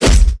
melee_punch.wav